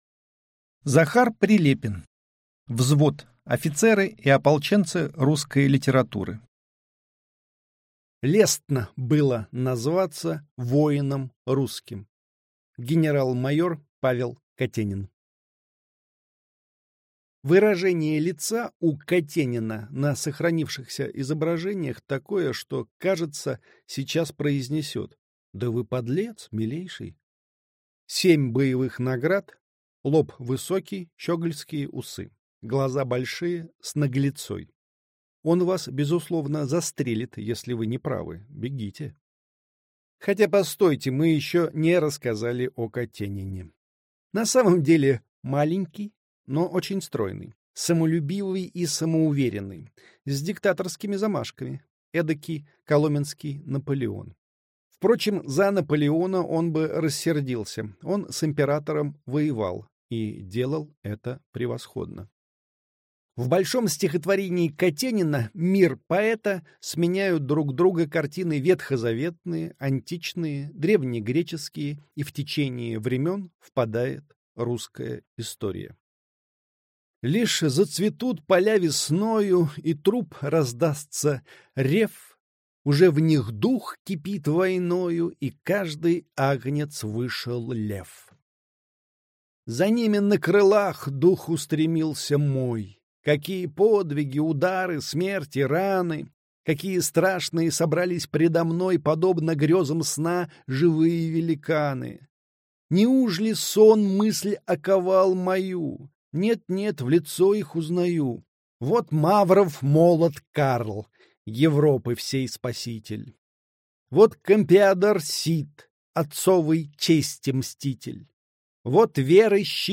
Аудиокнига Взвод. Офицеры и ополченцы русской литературы. Генерал-майор Павел Катенин | Библиотека аудиокниг